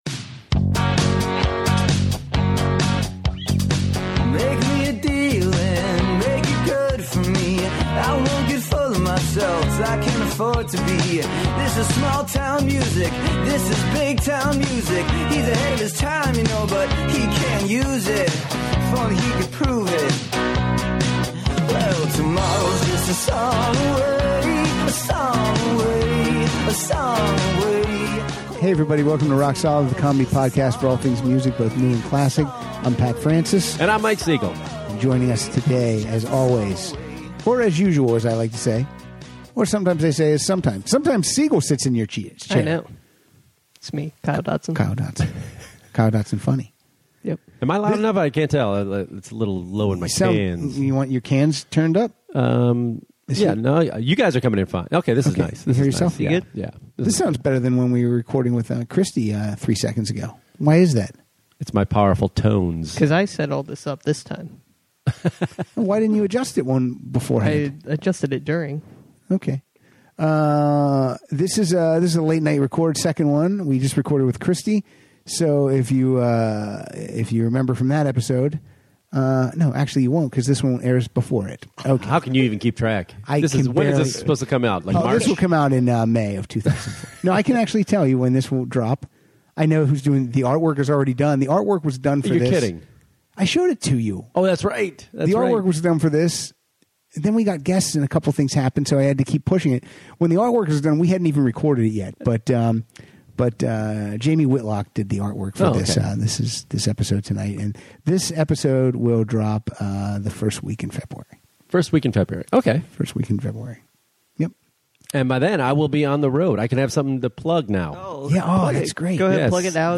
playing songs about war and more importantly peace.